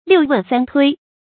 六问三推 liù wèn sān tuī
六问三推发音
成语注音ㄌㄧㄨˋ ㄨㄣˋ ㄙㄢ ㄊㄨㄟ